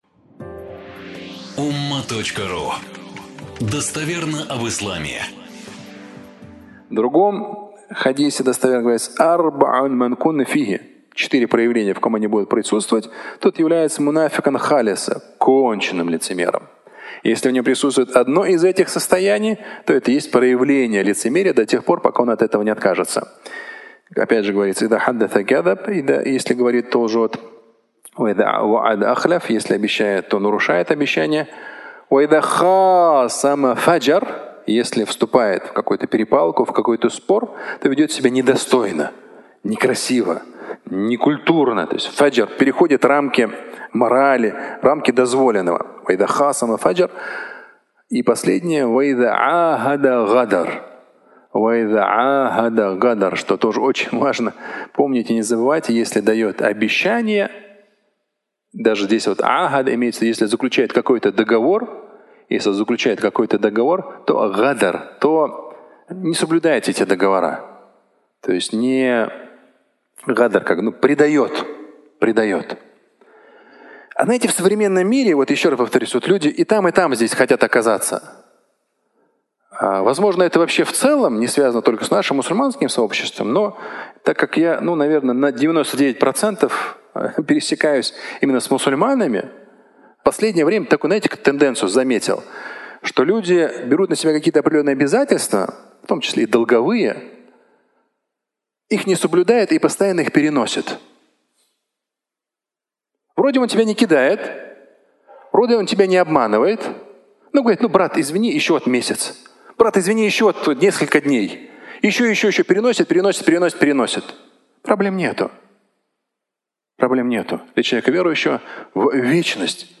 (аудиолекция)
Фрагмент пятничной проповеди